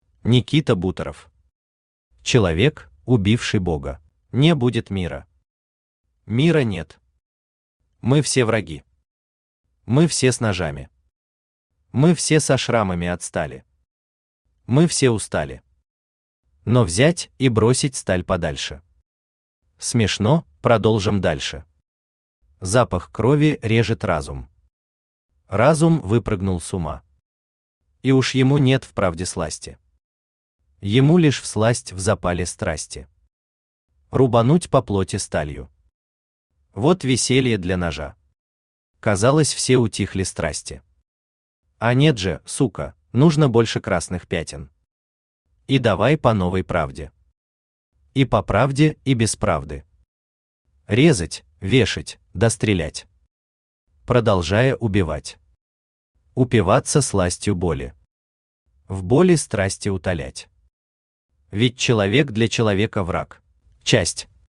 Аудиокнига Человек, убивший бога | Библиотека аудиокниг
Aудиокнига Человек, убивший бога Автор Никита Сергеевич Буторов Читает аудиокнигу Авточтец ЛитРес.